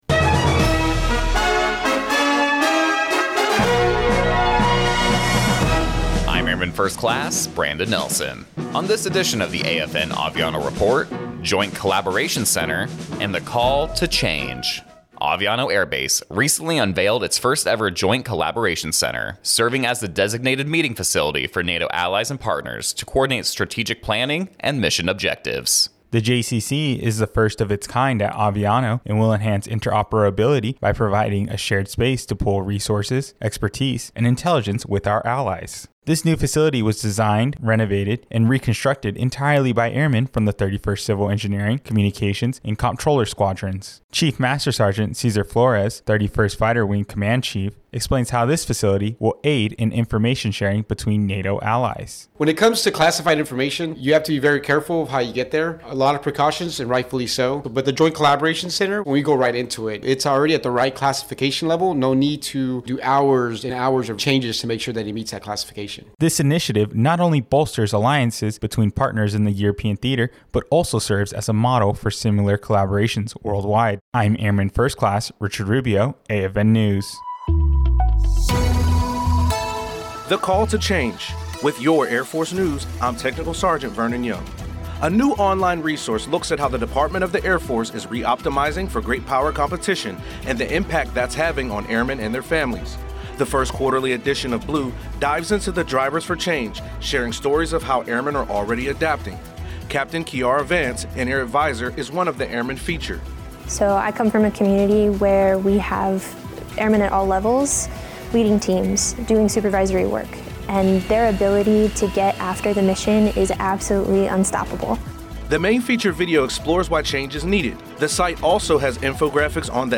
American Forces Network (AFN) Aviano radio news reports on the newly unveiled Joint Collaboration Center at Aviano Air Base. The facility will serve as the designated meeting facility for NATO allies to coordinate strategic planning and mission objectives.